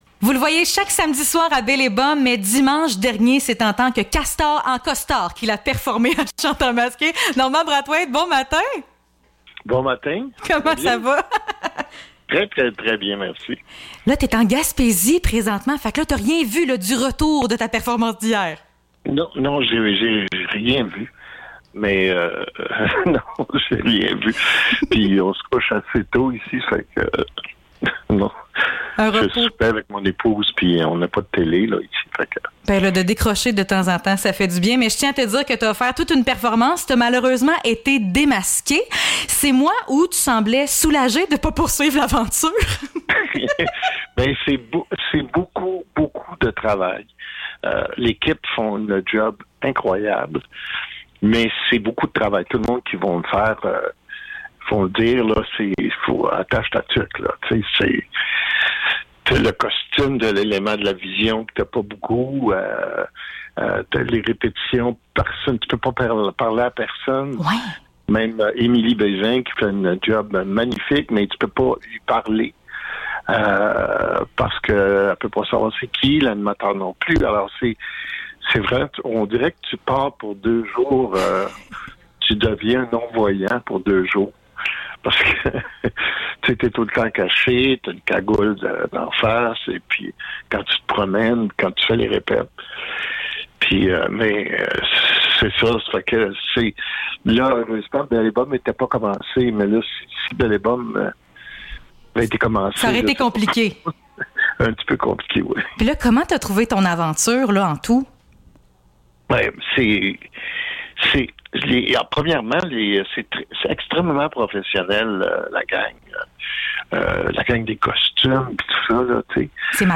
Entrevue avec Normand Brathwaite (Chanteur masqué)